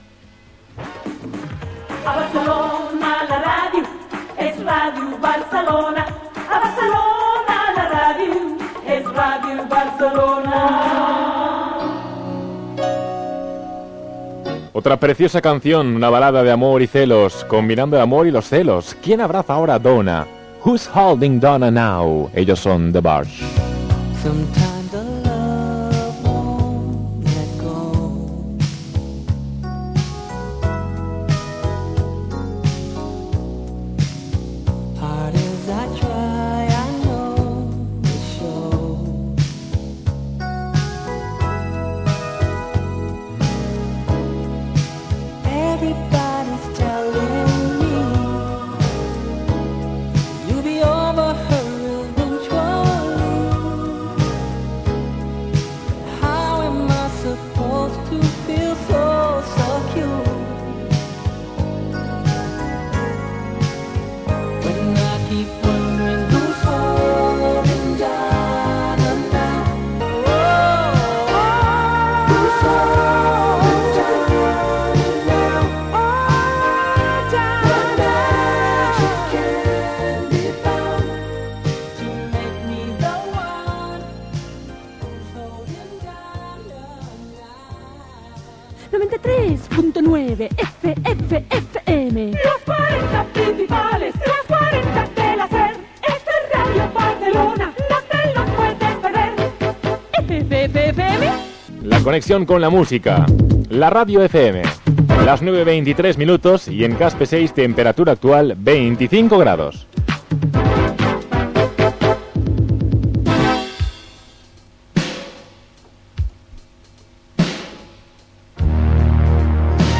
Presentació temes musicals i temperatura.
Musical